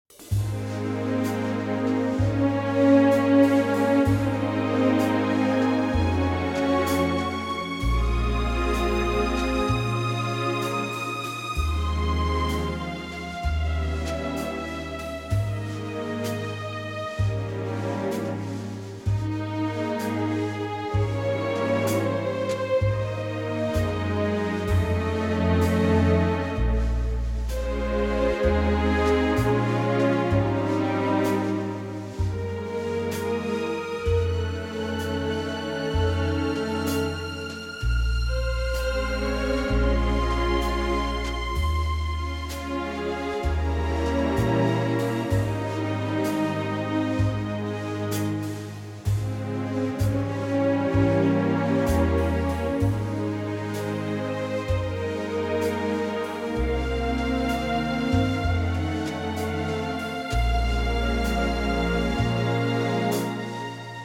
key - Ab - vocal range - Eb to G